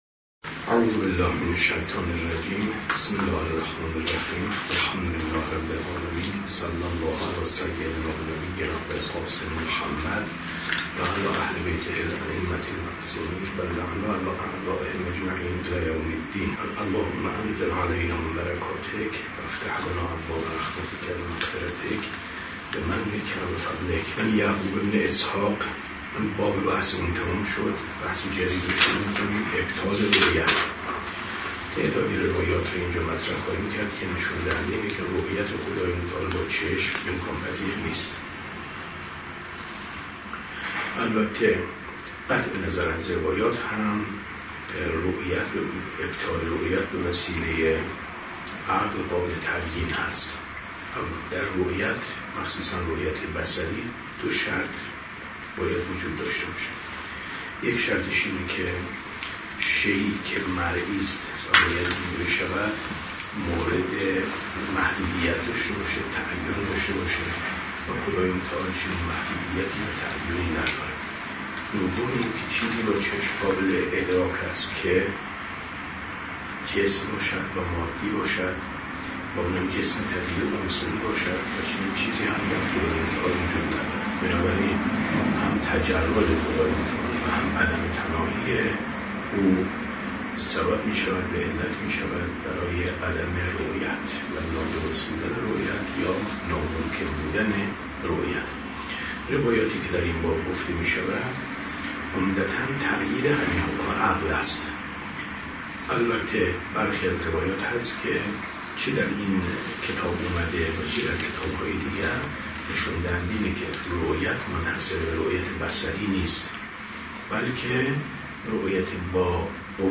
کتاب توحید ـ درس 47 ـ 24/ 10/ 95